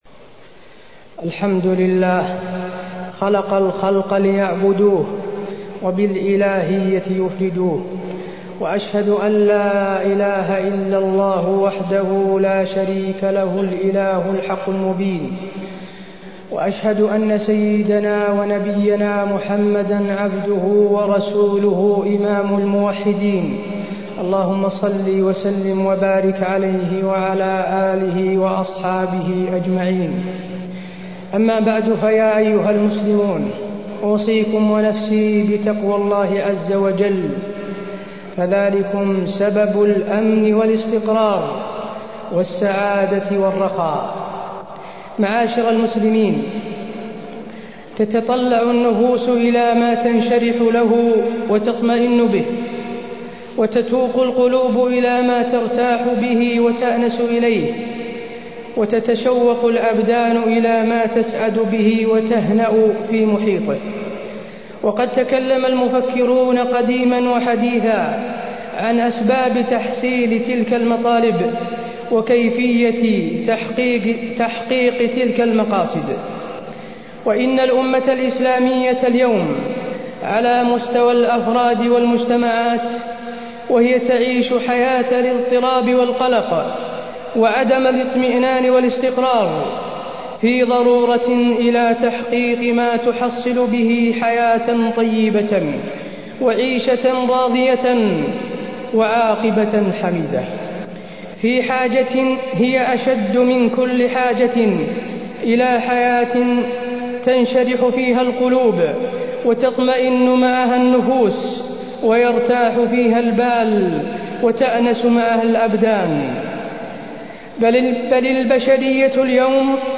تاريخ النشر ٢٢ ربيع الأول ١٤٢٤ هـ المكان: المسجد النبوي الشيخ: فضيلة الشيخ د. حسين بن عبدالعزيز آل الشيخ فضيلة الشيخ د. حسين بن عبدالعزيز آل الشيخ احفظ الله يحفظك